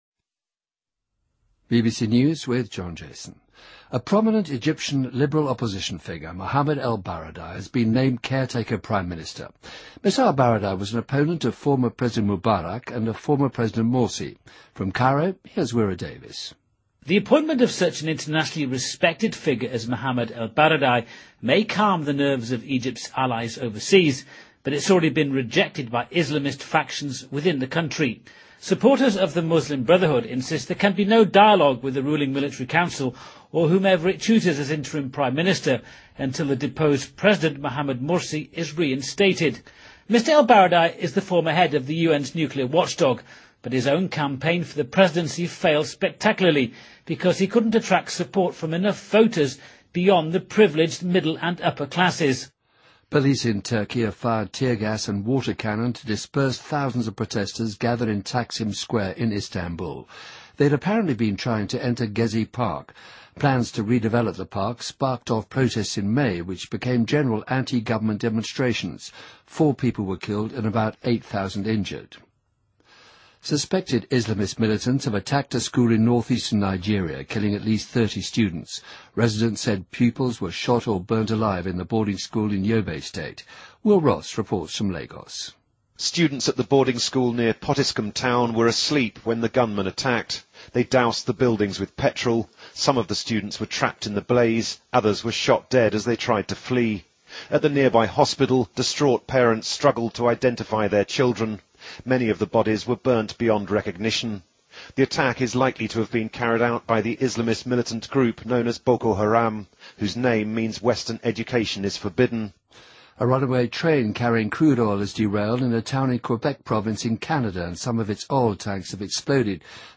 BBC news,韩国一架客机在美国旧金山机场降落时坠毁